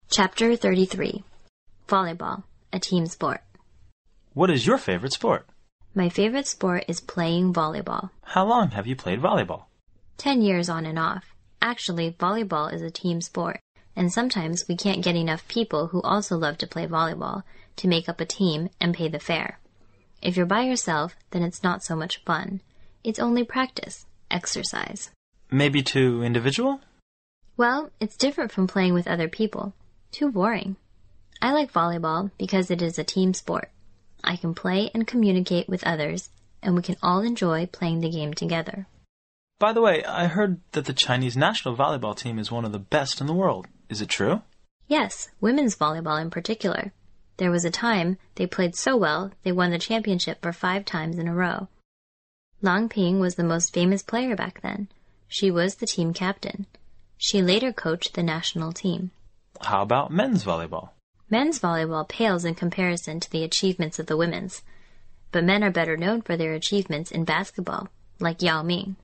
原汁原味的语言素材，习得口语的最佳语境。